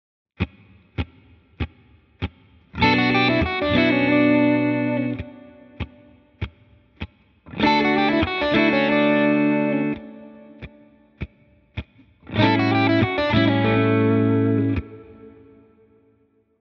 Ha a fenti dallam alá Hm akkordot tennénk, h-mollnak éreznénk a hangnemét. Ha D akkordot, akkor d-dúrnak.
Habár mindnek 2# az előjegyzése, és hangkészletük megegyezik, a közeg határozza meg, milyen hangnemmel van dolgunk.